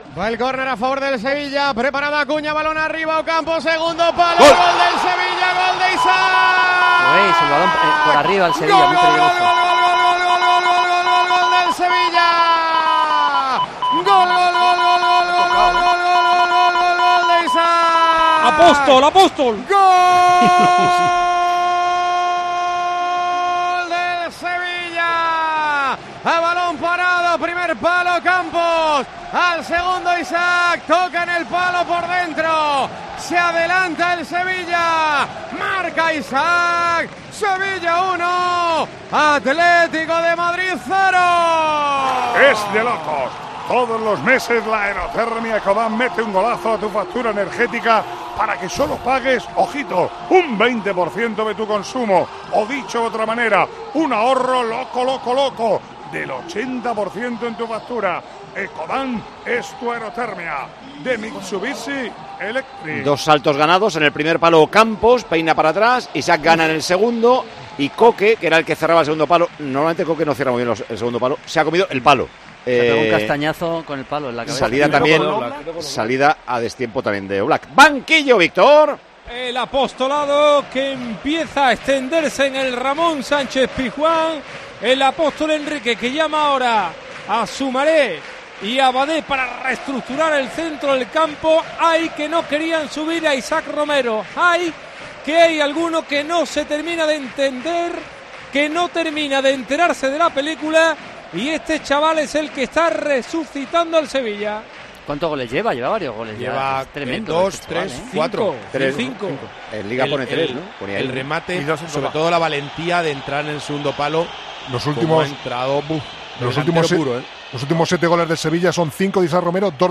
Micrófono de COPE en el Sánchez Pizjuán
Así vivimos en Tiempo de Juego el Sevilla - Atlético